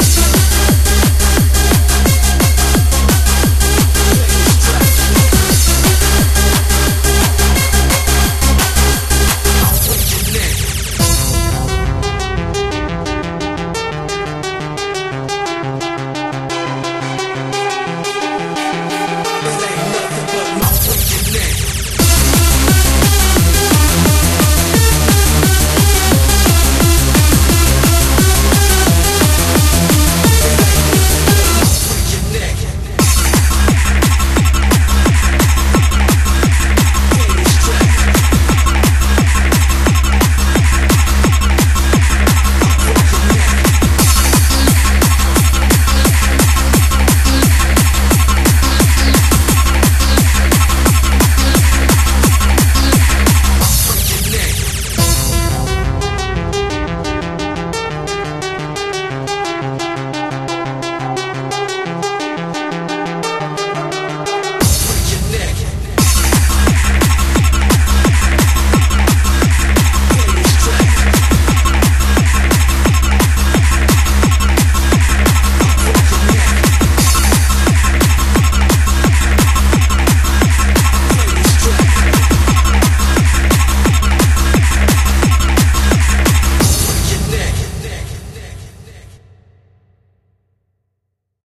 BPM175
Audio QualityPerfect (High Quality)